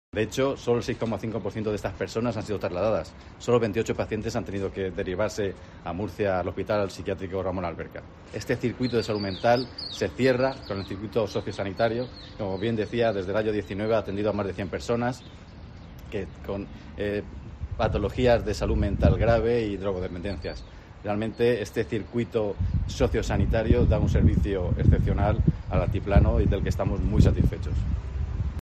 Francisco Ponce, gerente del Servicio Murciano de Salud